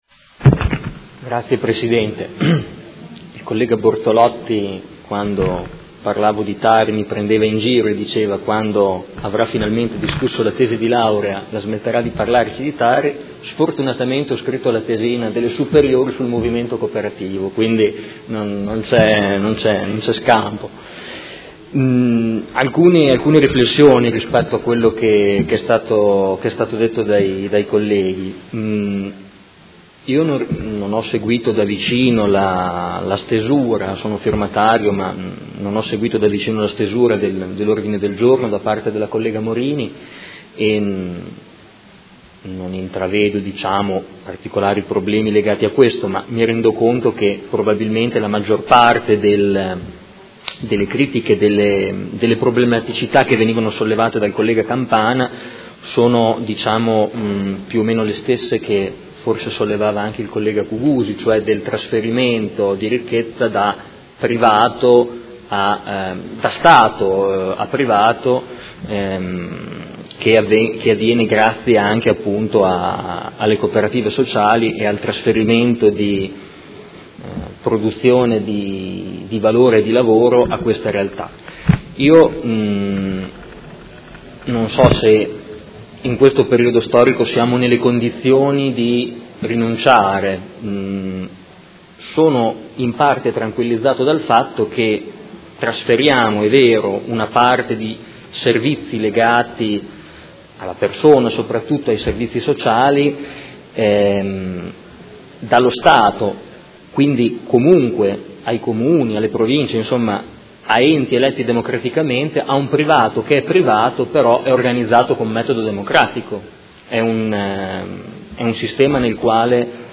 Seduta del 6 ottobre. Ordine del Giorno presentato dai Consiglieri Morini, Venturelli, Forghieri, Fasano, Pacchioni, Stella, Di Paova, Malferrari, Poggi, Lenzini e Trande (P.D.) avente per oggetto: La Cooperazione sociale, modello imprenditoriale che garantisce coesione nella Città.